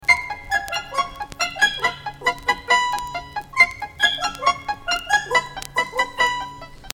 Инструментальный ансамбль